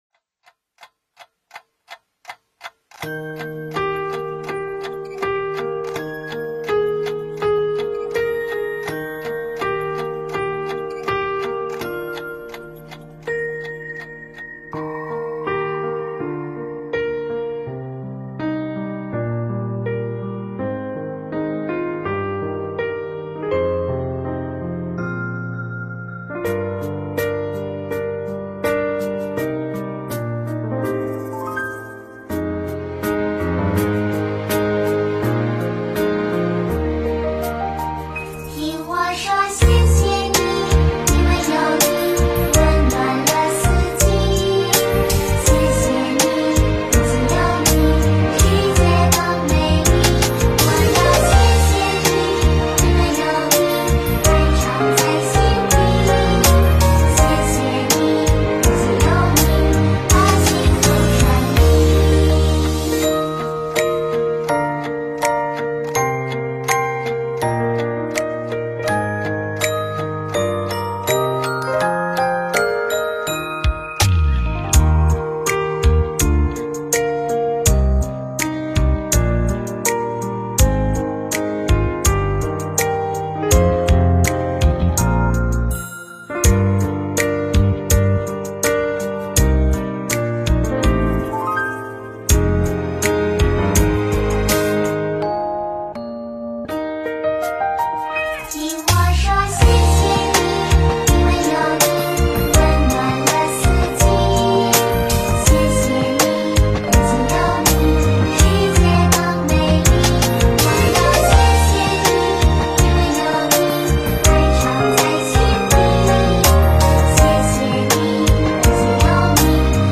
音频：元宵节温哥华观音堂庆元宵！2023年！